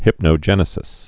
(hĭpnō-jĕnĭ-sĭs)